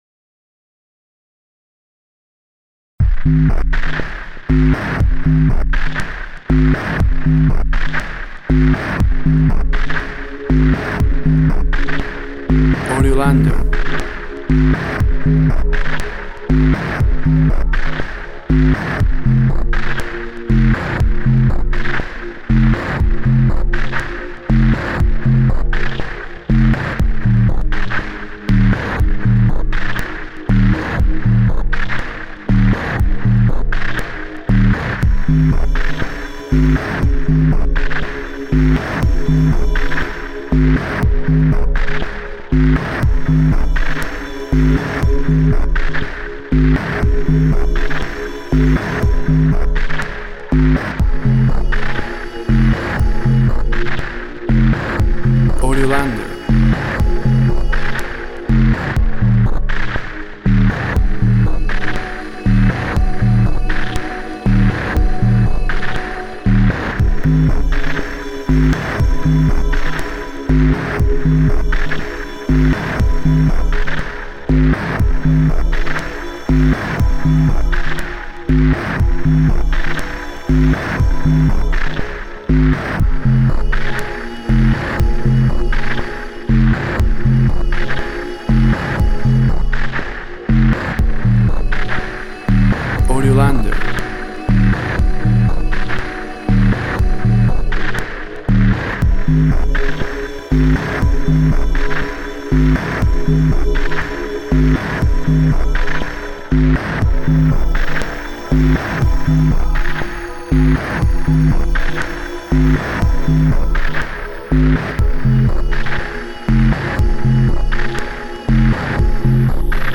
Tempo (BPM) 80